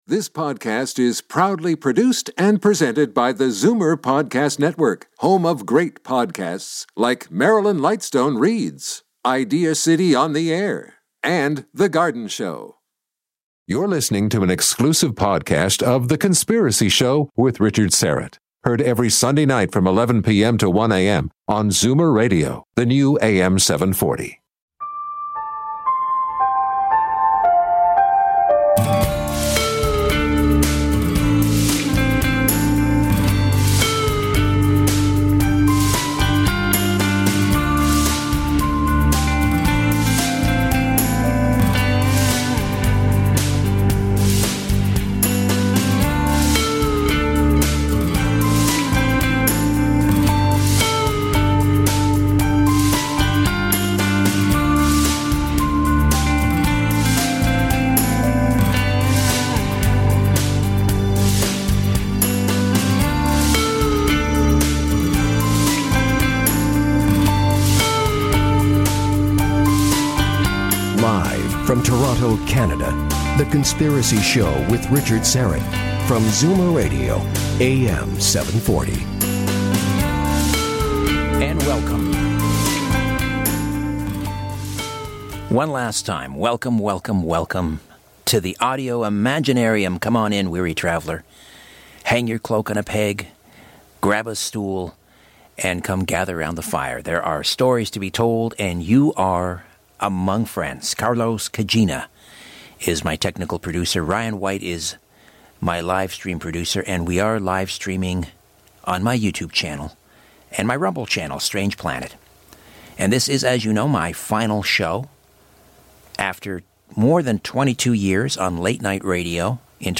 Every Sunday Night from 11pm to 1am on Zoomer Radio AM740 and FM96.7